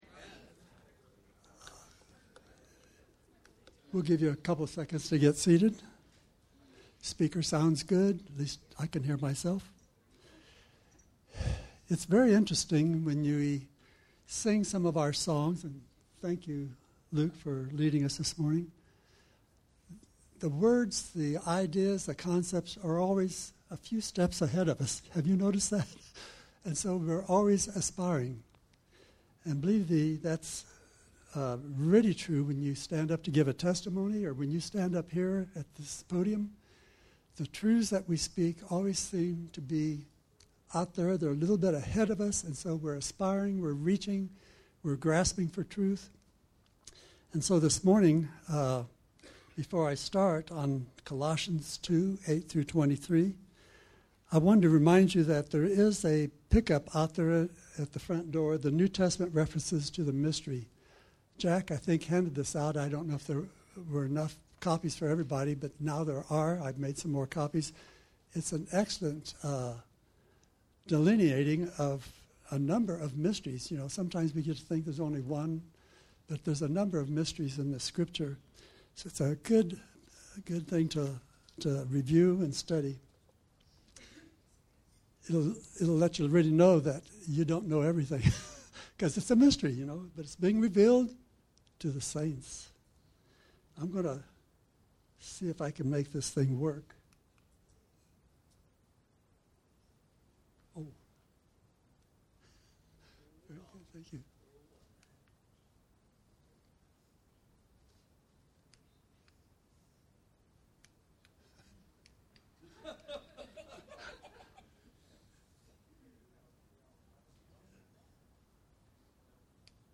Colossions 2:8-23 Service Type: Sunday Morning « The “Why” Act Like You’re Risen With Christ »